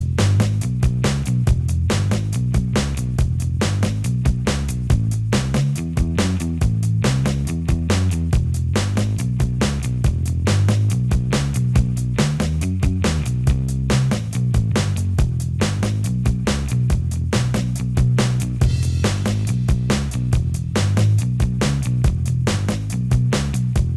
Minus Guitars Pop (1960s) 2:34 Buy £1.50